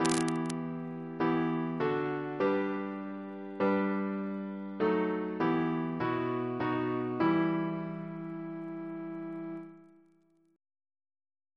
Single chant in E minor Composer: John Blow (1648-1708), Organist of Westminster Abbey Reference psalters: ACB: 373; ACP: 75; H1940: 670 689; H1982: S199; OCB: 169; PP/SNCB: 233